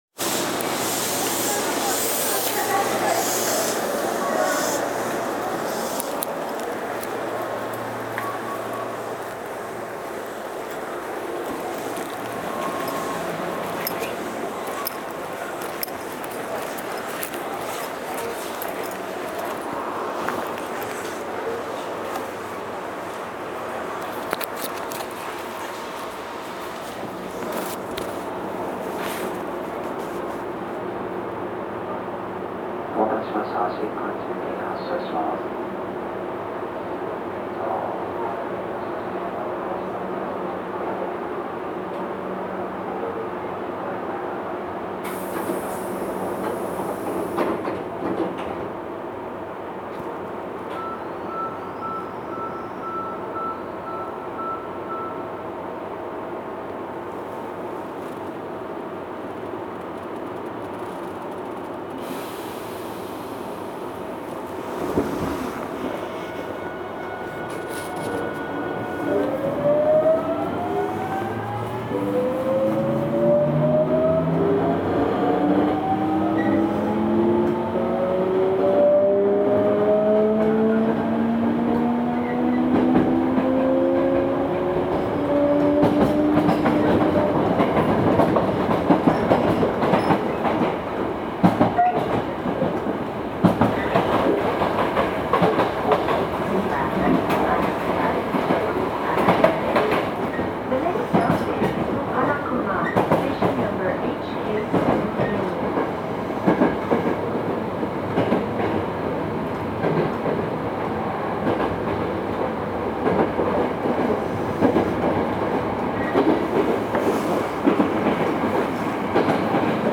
走行機器はGTO素子によるVVVFインバータ制御で、モーターはかご形三相交流誘導電動機で、将来の速度向上に備えて定格170kWと7000系の150kWより大きくなっており、更に定速制御装置も備えます。
走行音
未更新車
録音区間：神戸三宮～花隈(特急)(お持ち帰り)